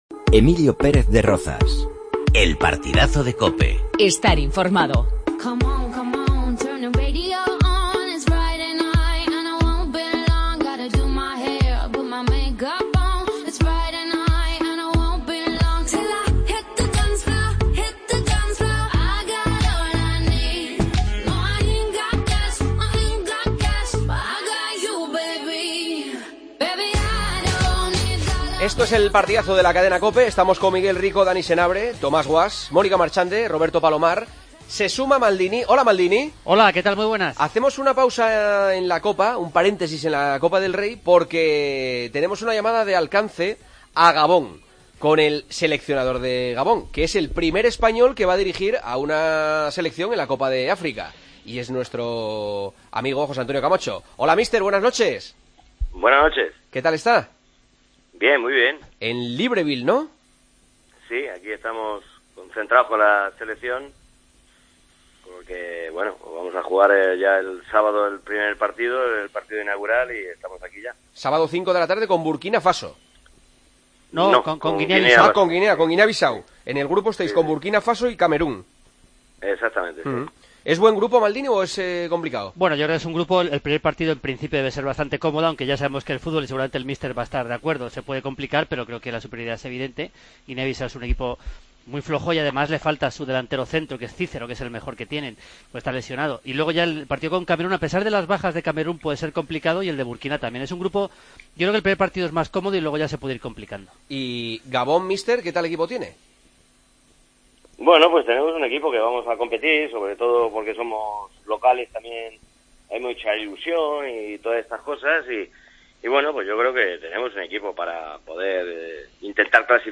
Entrevistas a José Antonio Camacho